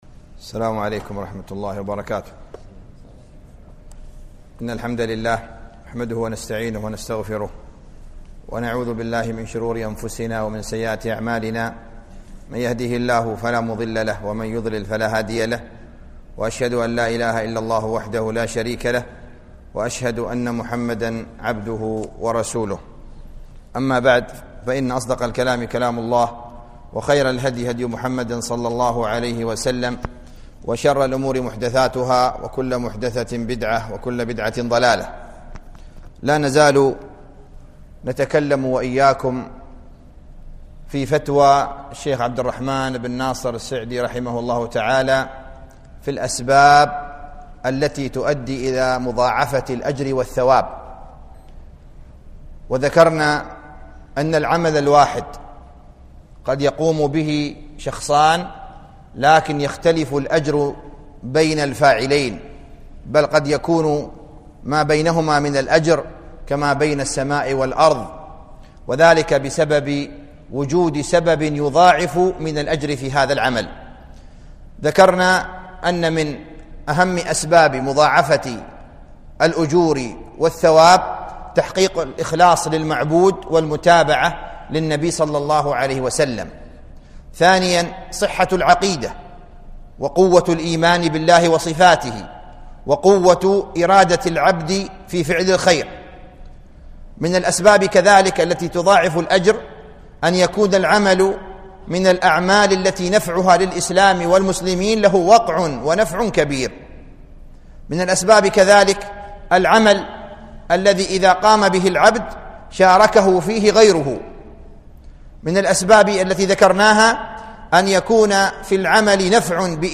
الدرس الخامس والأخير